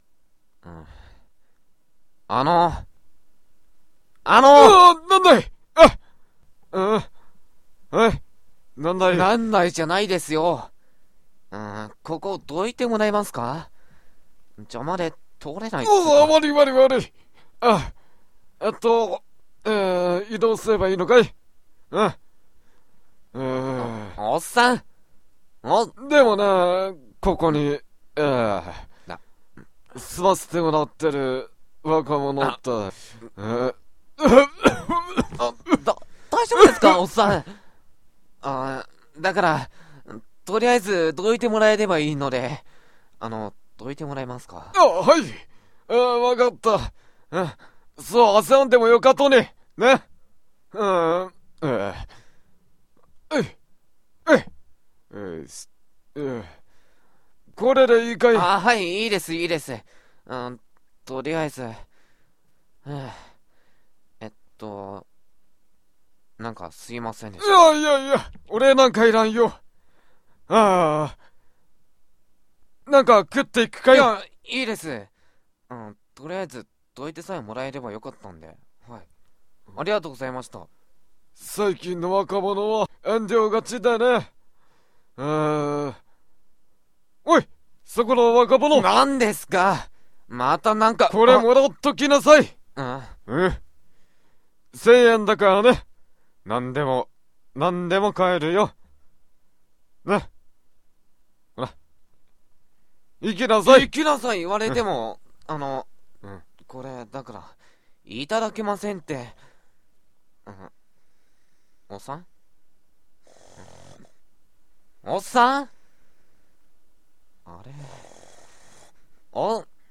1人3役